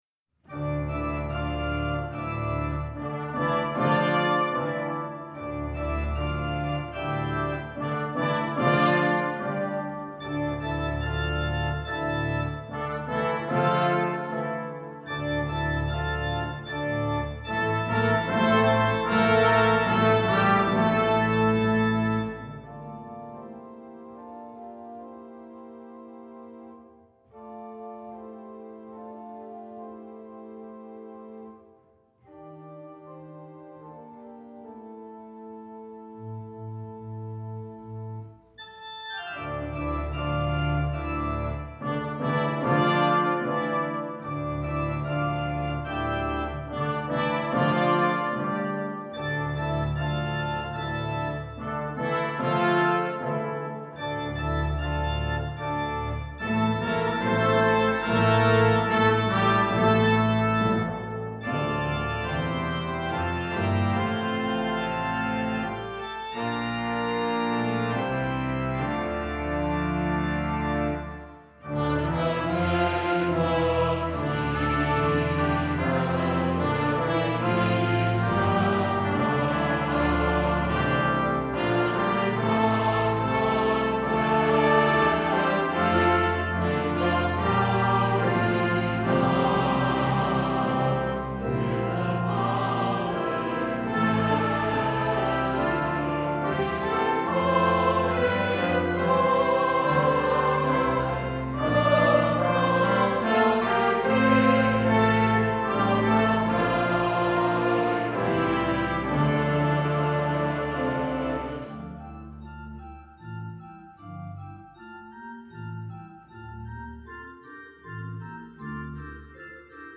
Voicing: SATB, Optional Unison Voices, Congregation